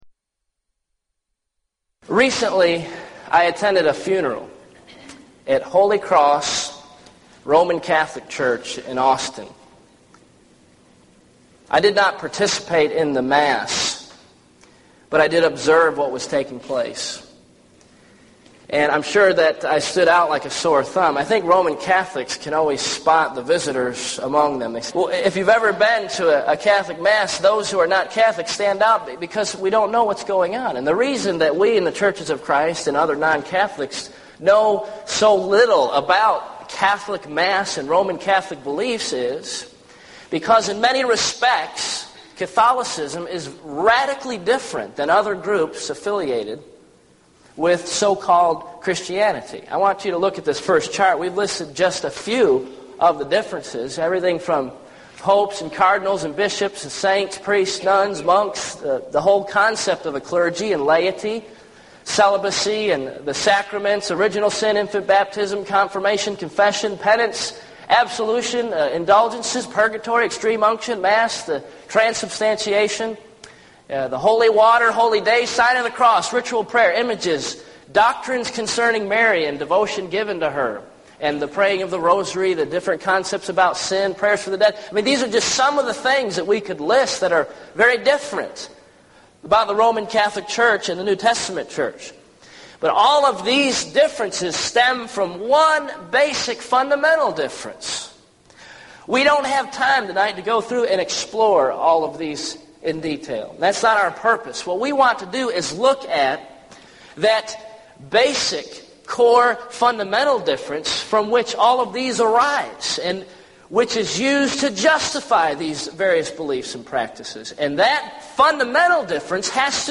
Event: 1999 Gulf Coast Lectures
lecture